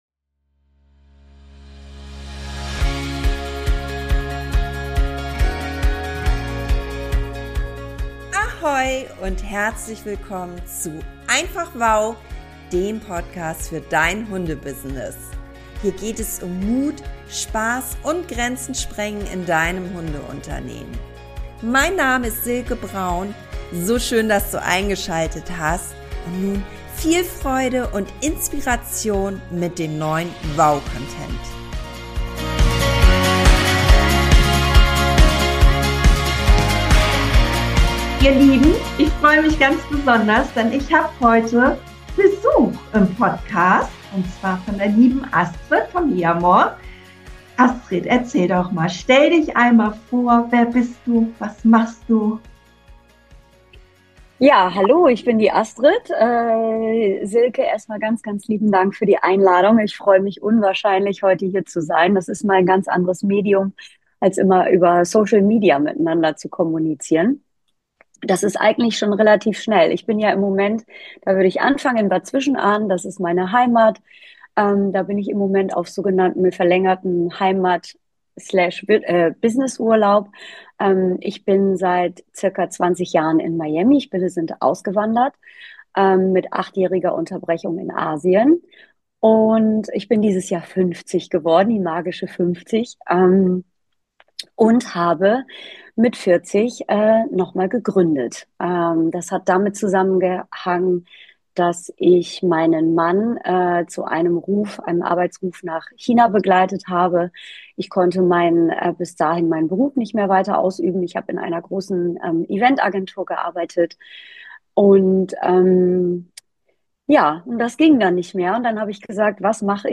Es ist ein Gespräch voller Energie, Tiefe und Leichtigkeit geworden.